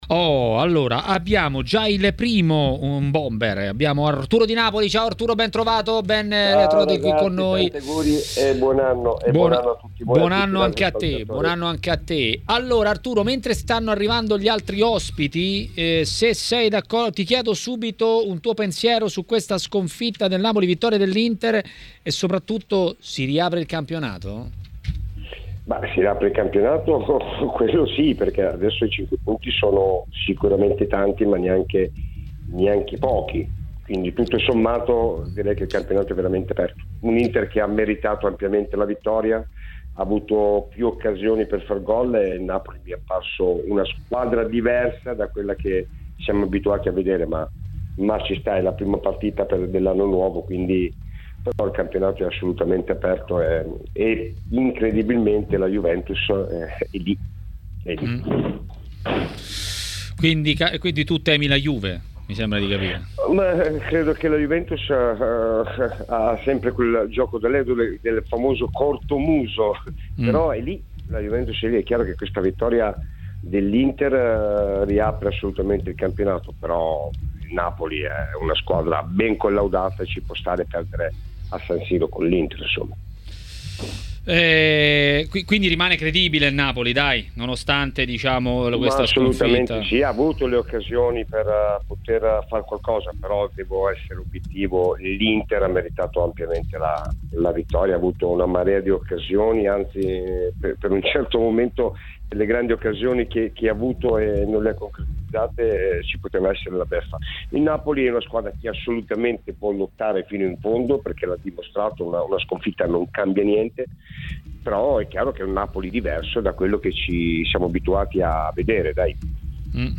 è intervenuto ai microfoni di TMW Radio